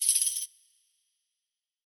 tambourine 1.0.wav